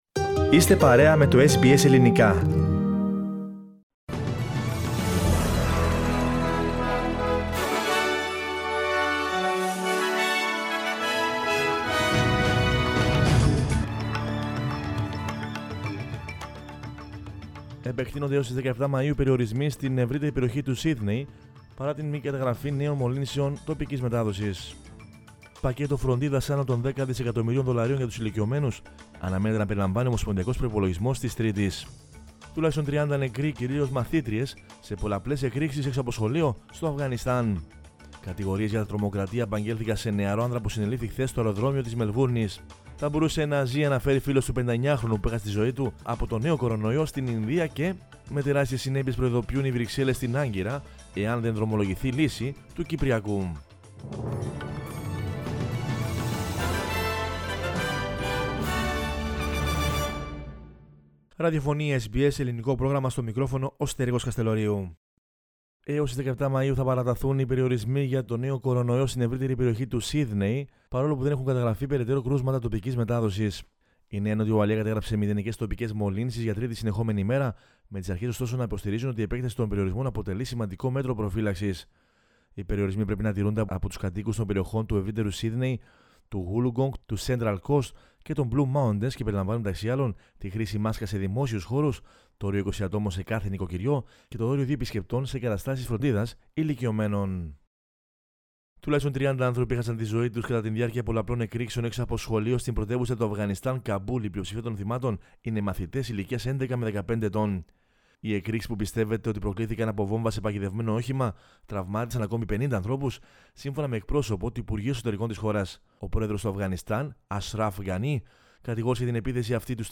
News in Greek from Australia, Greece, Cyprus and the world is the news bulletin of Sunday 9 May 2021.